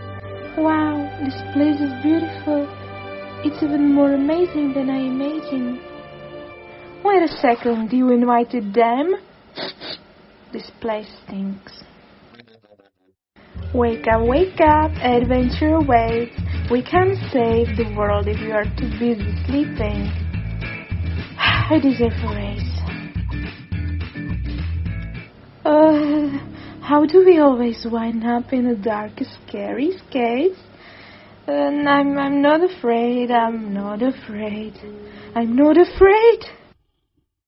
• Záznam bez šumu: Používáme vybavení a techniku pro čistý a jasný zvuk.
• dabing challenge pro různé styly předpřipravených textů a hlasů v ENG